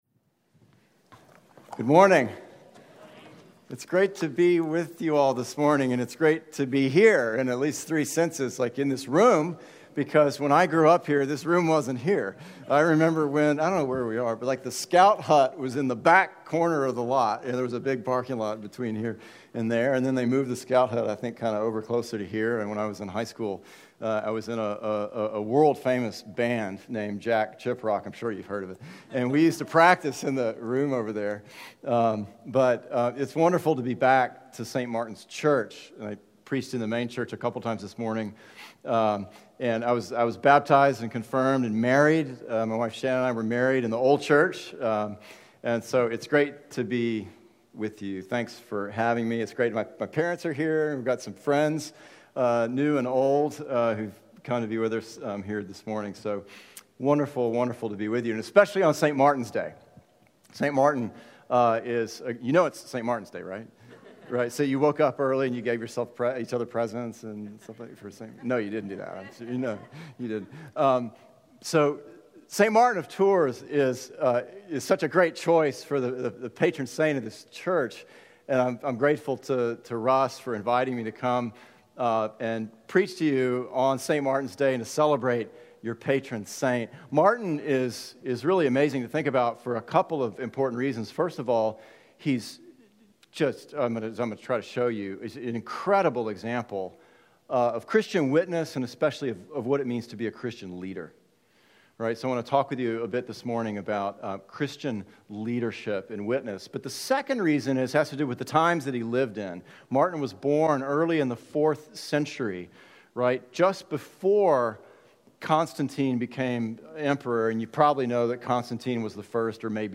Altar – Sermon